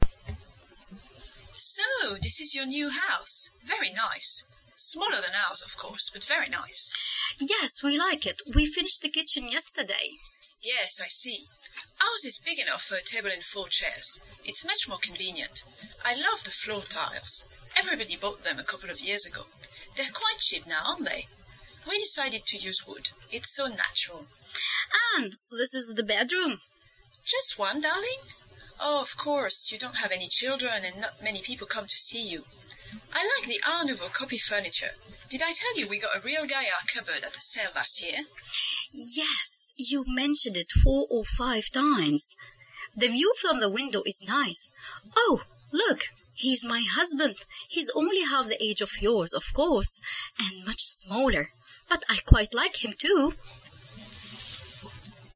[ChoiceLetter] Your score is: Show the whole text Show only the last part of the text OK Listen to the dictation: sound/n2_ch08_not-so-good.MP3 Web dictaphone - Record yourself Record Stop